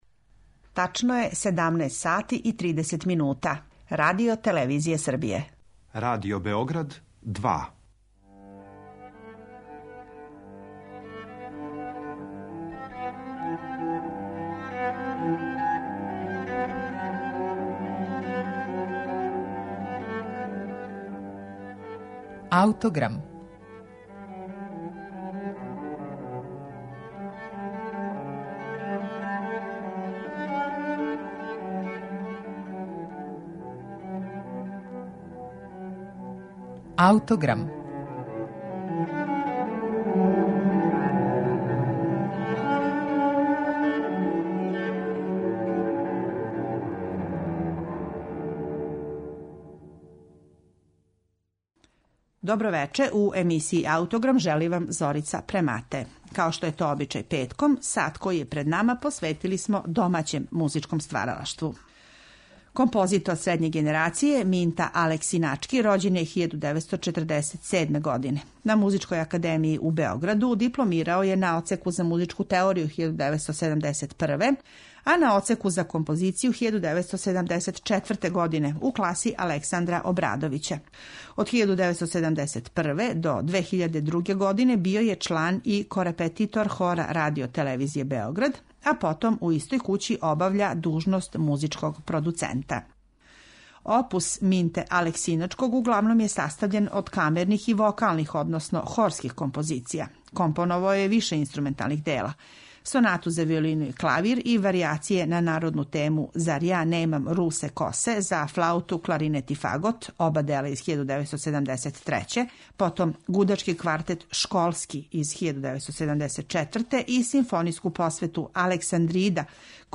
симфонијску посвету
на њеном премијерном извођењу.